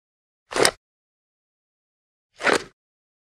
Звуки выстрела, мультфильмов
На этой странице собраны звуки выстрелов из популярных мультфильмов: пистолеты, ружья, лазерные пушки и другие забавные эффекты.
Пистолет из кобуры и обратно